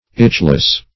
Itchless \Itch"less\, a. Free from itching.